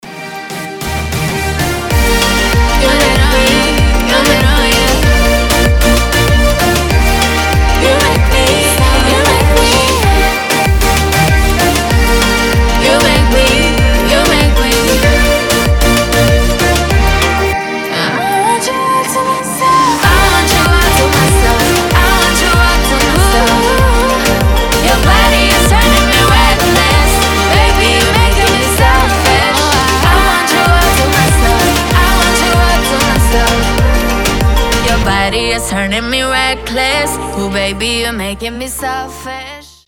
• Качество: 320, Stereo
женский вокал
заводные
Dance Pop
Moombahton